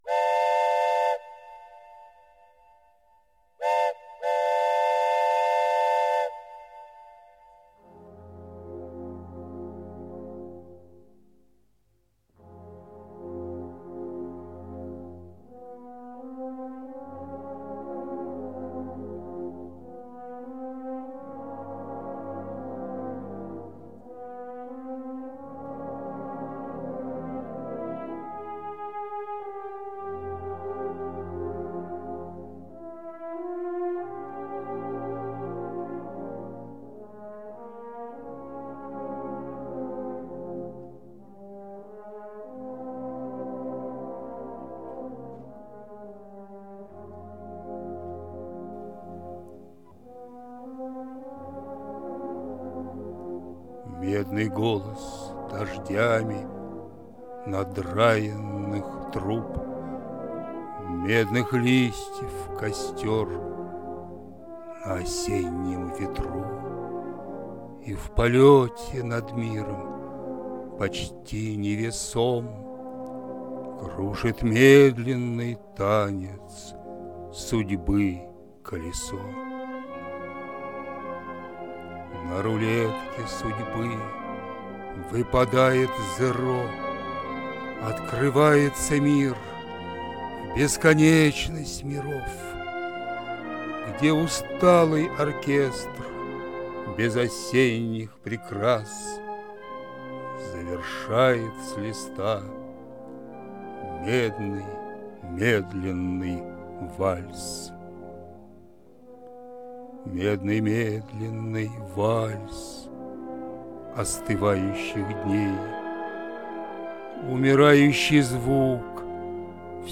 • Жанр: Декламация